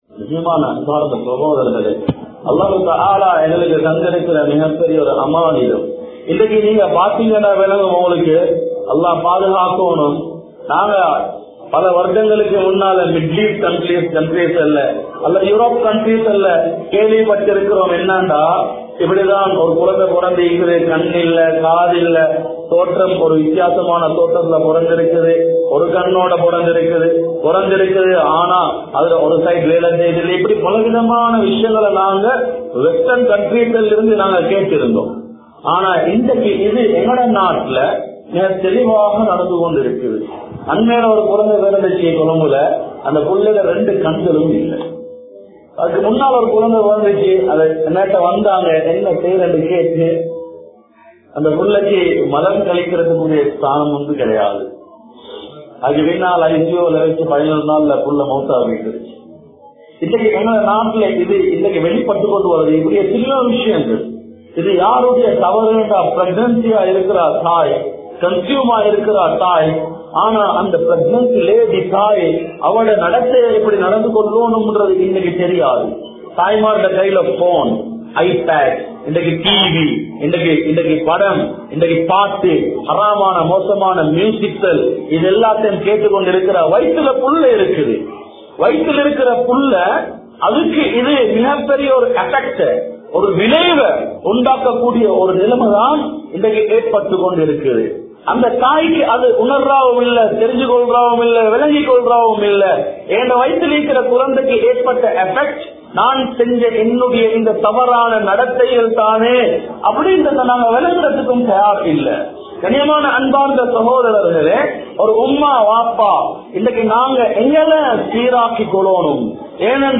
Petrorum, Meadiyaavum, Pillaikalum(பெற்றோரும், மீடியாவும், பிள்ளைகளும்) | Audio Bayans | All Ceylon Muslim Youth Community | Addalaichenai
Bandarawela, Pallathearuwa Seeloya Jumua Masjith